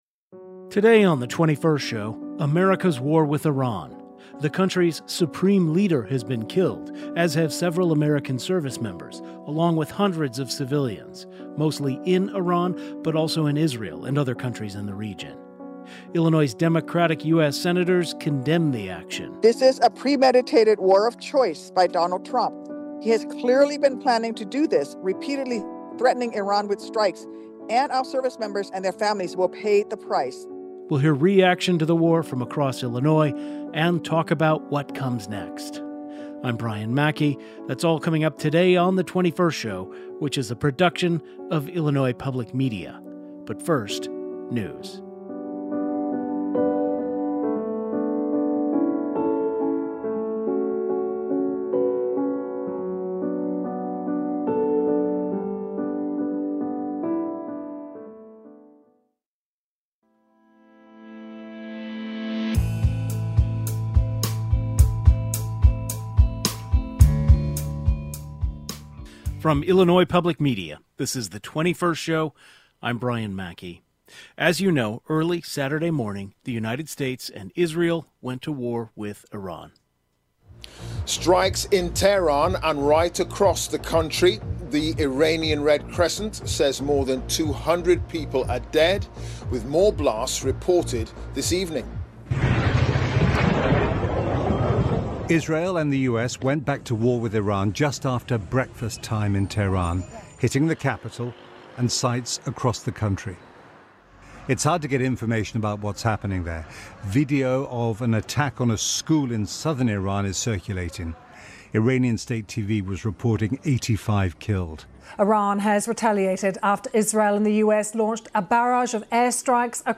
A panel of political and foreign affairs experts including two Iranian-Americans discuss the possibility of regime change in Iran, the legality and necessity of the military assaults, and the role of Israel in all of this.